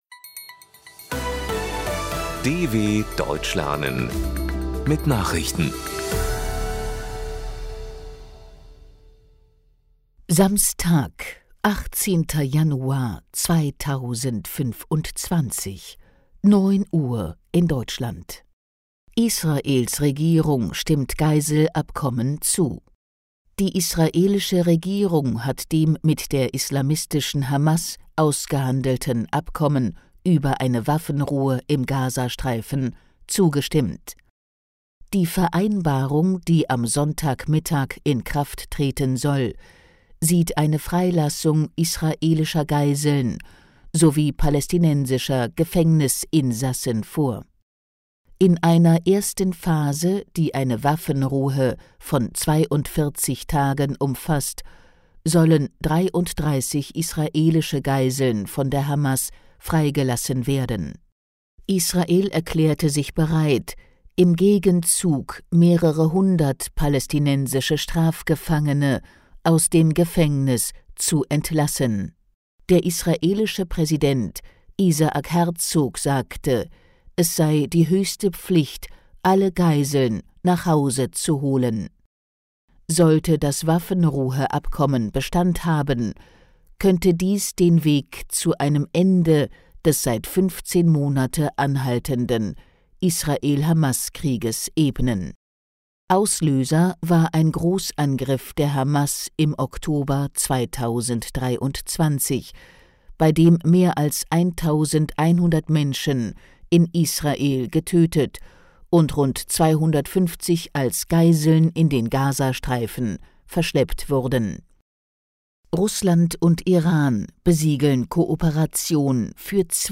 Das langsam und verständlich gesprochene Audio trainiert das Hörverstehen.